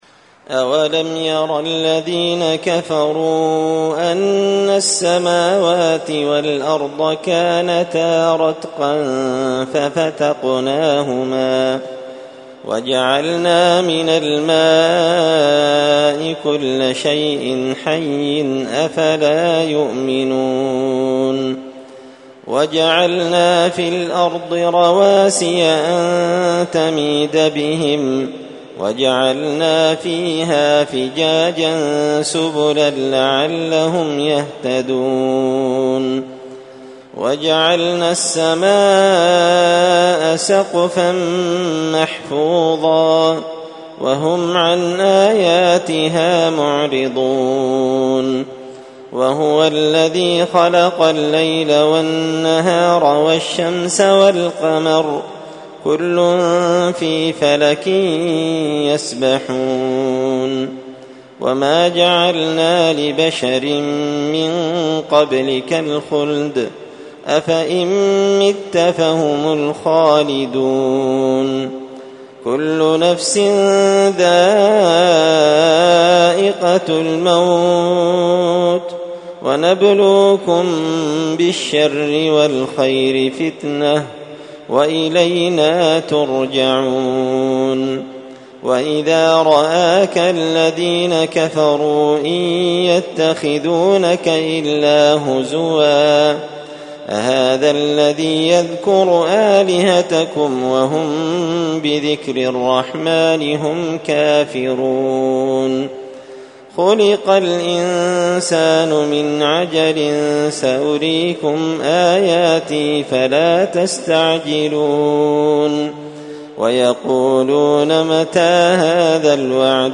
تلاوة من سورة الأنبياء
الأثنين 16 ذو القعدة 1444 هــــ | قران كريم | شارك بتعليقك | 25 المشاهدات
تلاوة-من-سورة-الأنبياء.mp3